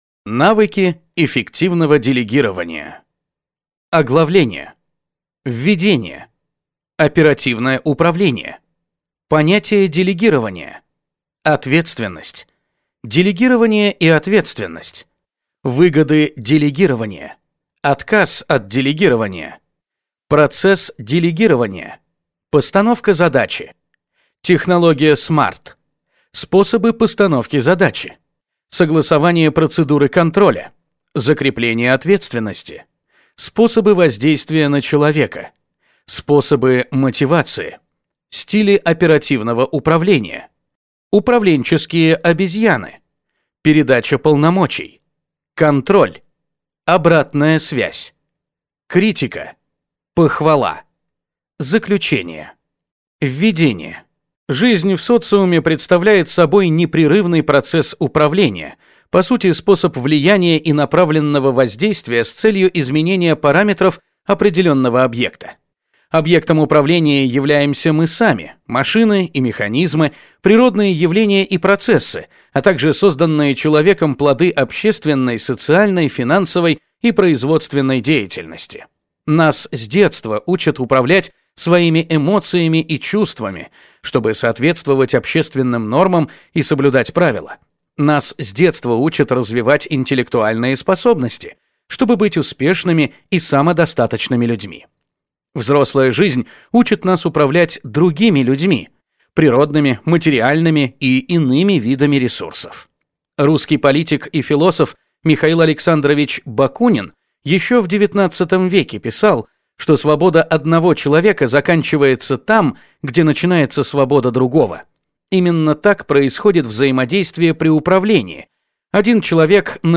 Аудиокнига Навыки эффективного делегирования | Библиотека аудиокниг